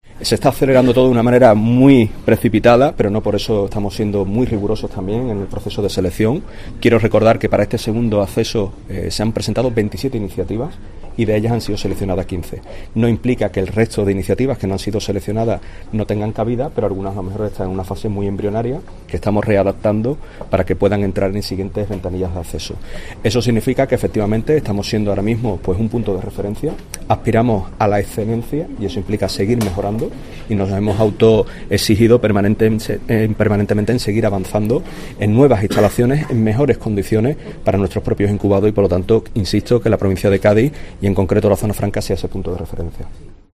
Fran González, Delegado del Estado para la Zona Franca de Cádiz, habla sobre los nuevos incorporados a Incubaz